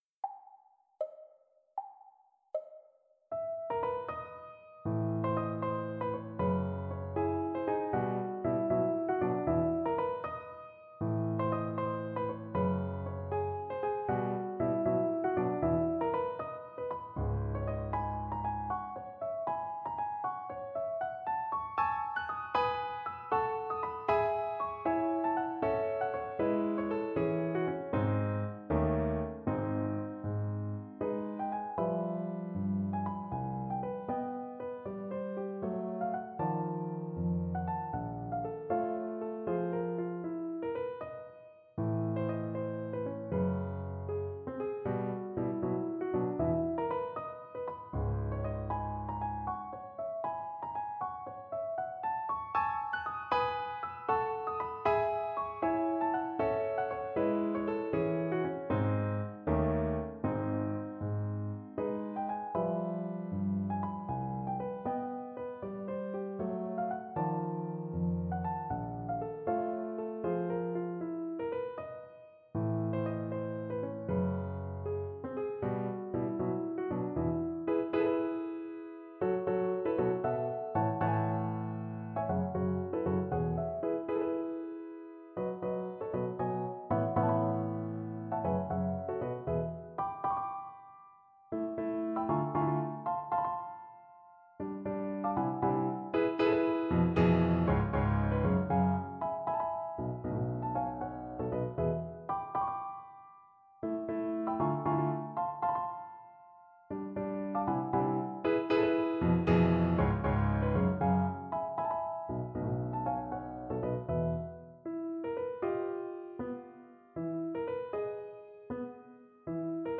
Without Pianist 2